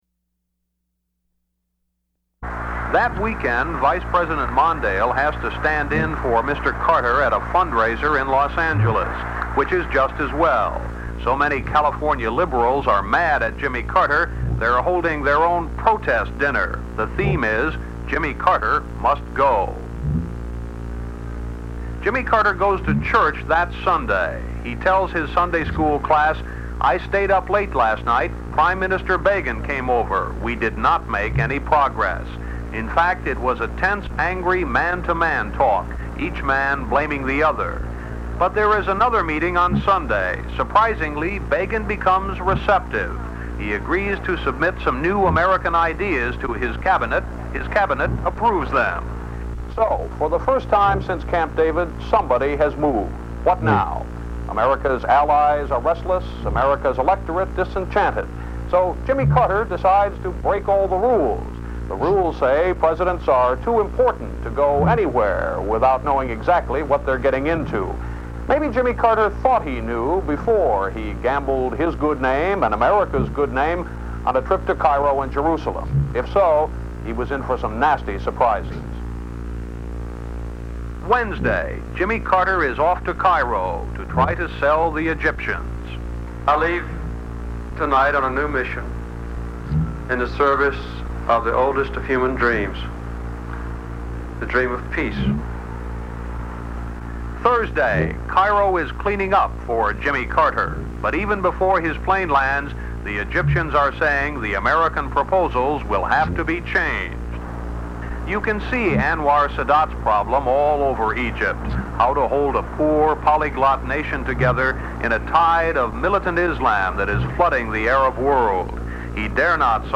Report on the President's week in Cairo and Jerusalem, with the voices of Carter, Sadat, Begin, and Brzezinski